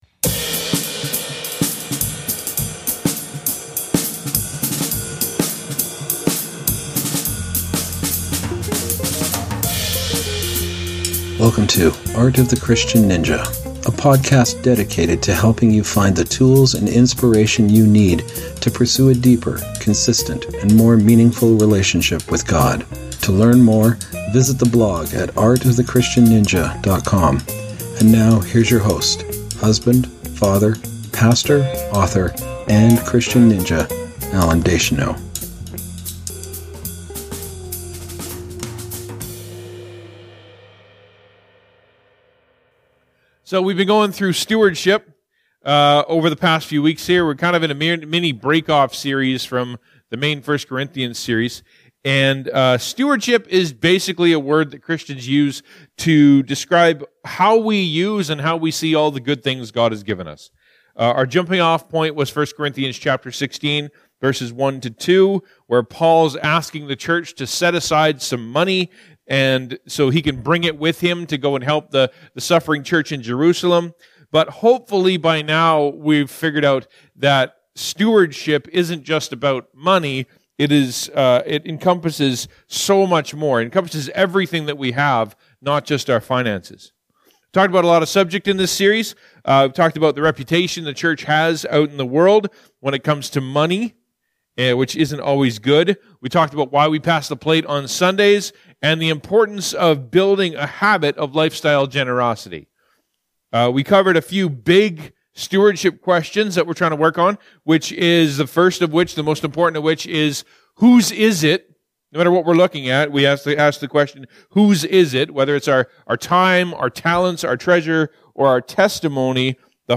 Audio: Sermon Text: We’ve been working through a series on Stewardship over the past few weeks.